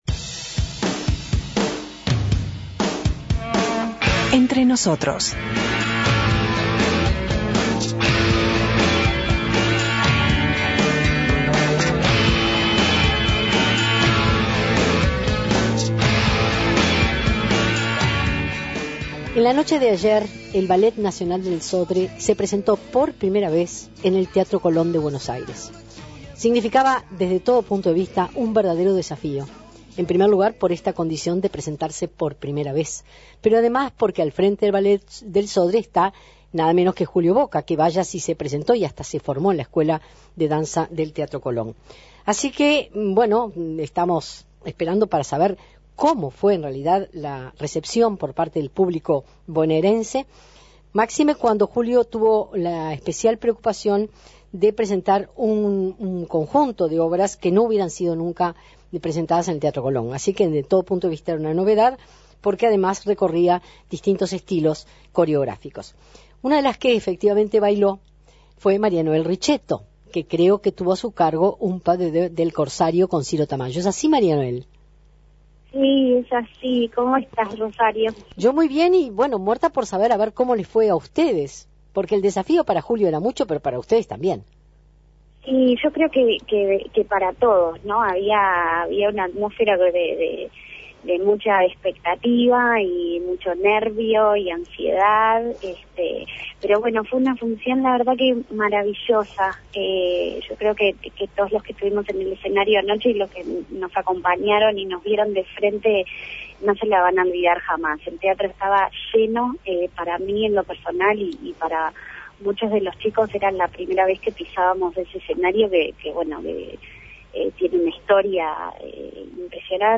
Entrevista con María Noel Ricceto, balarina solista del ballet del Sodre, por el espectáculo de anoche en el Colón de Buenos Aires